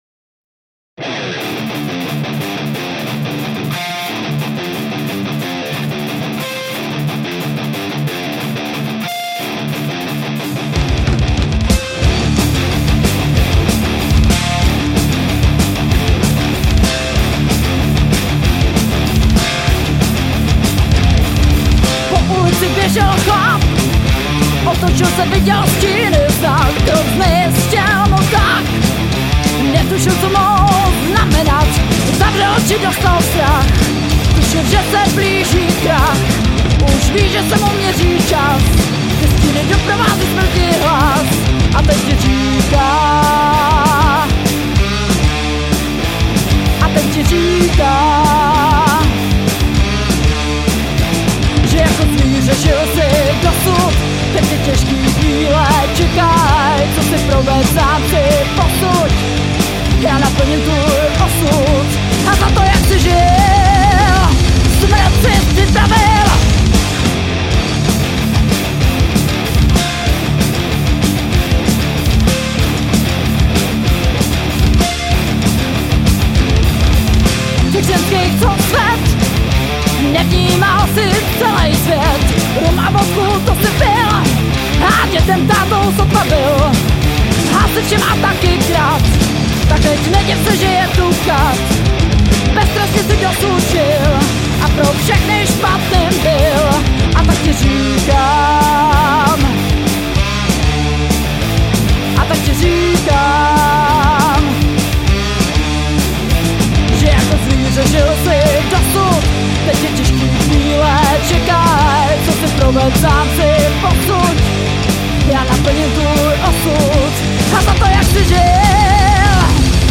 Žánr: Rock